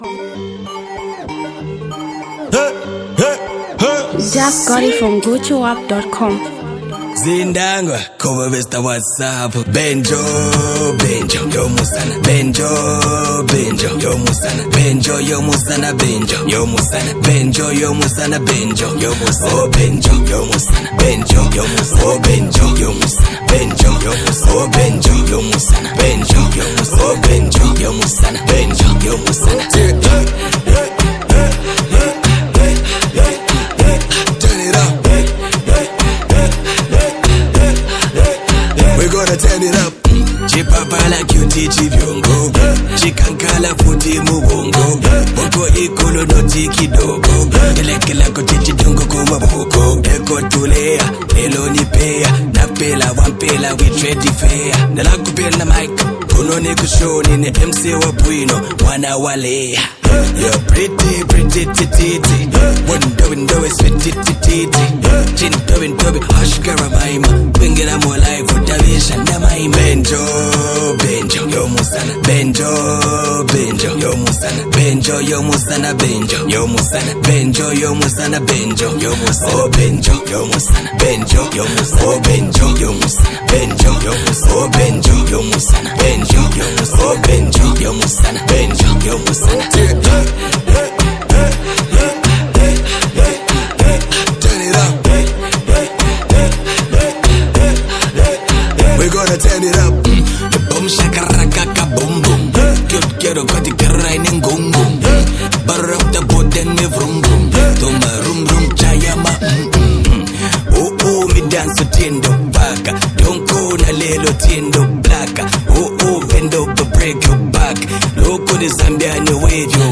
explosive new track
This high-energy single